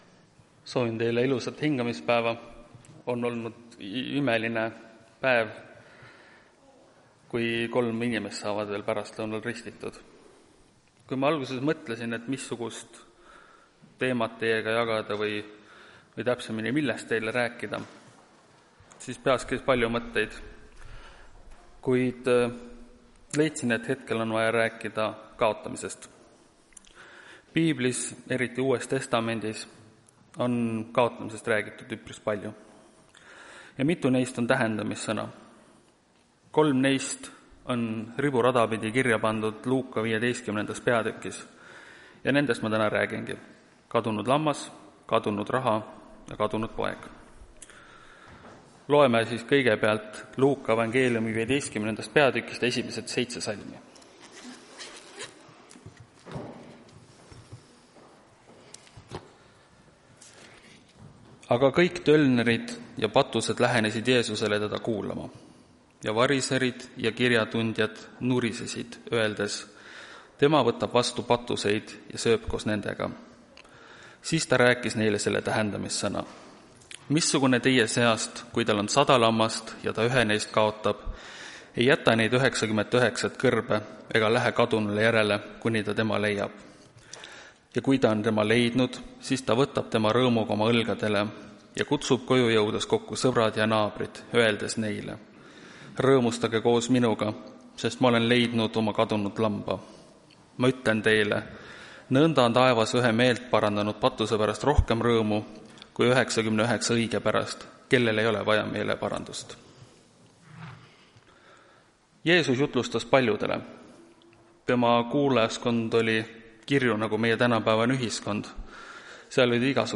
Tartu adventkoguduse 24.08.2024 hommikuse teenistuse jutluse helisalvestis.